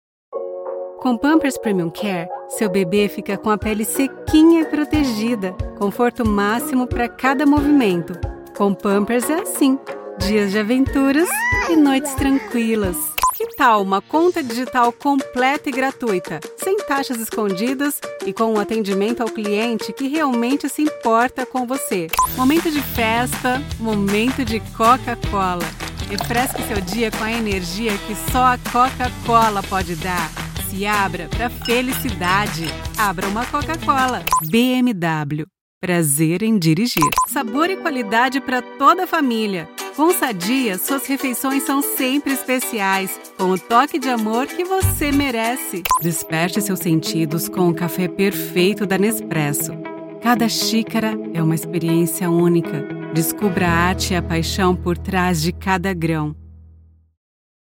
eine natürliche, vielseitige Stimme und einen neutralen Akzent
Konversation
Jung
Natürlich